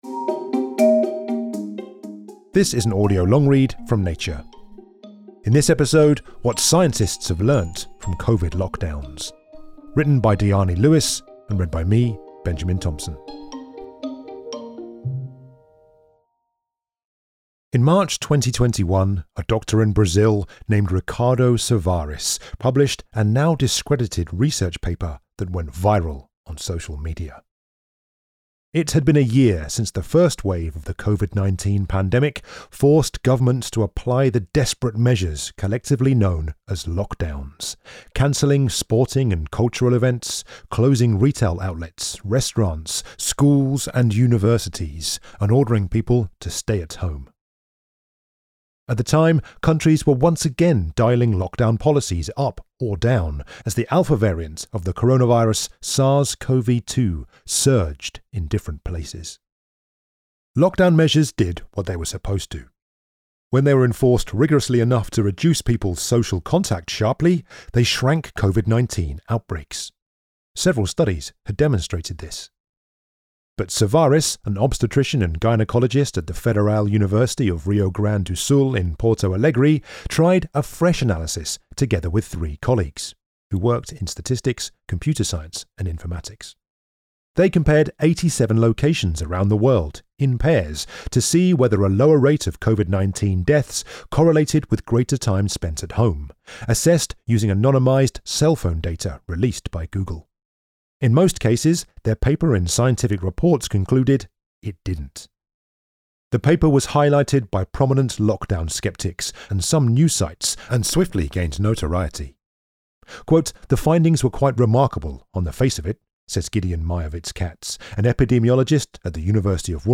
Audio long read: What scientists have learnt from COVID lockdowns - Science News